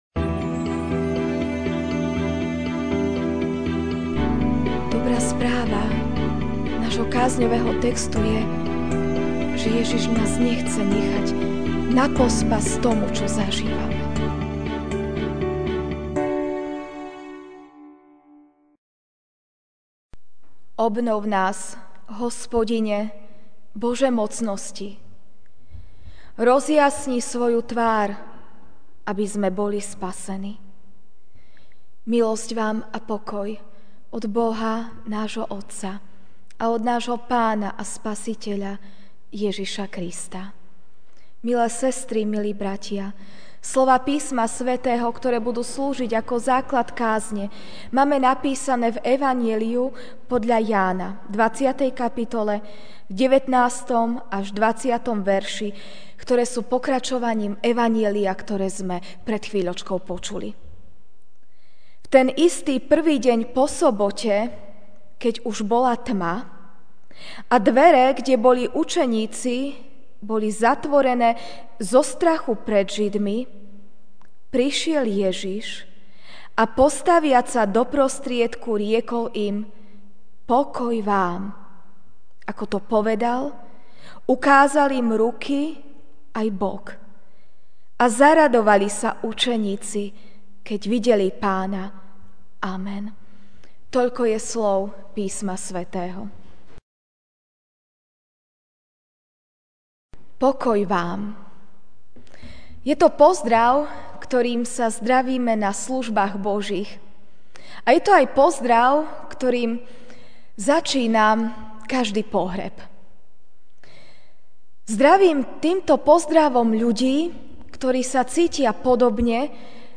Večerná kázeň: Zamknuté dvere (Ján 20, 19-20) V ten istý prvý deň po sobote, keď už bola tma a dvere, kde boli učeníci, boli zatvorené zo strachu pred Židmi, prišiel Ježiš a postaviac sa do prostriedku, riekol im: Pokoj vám!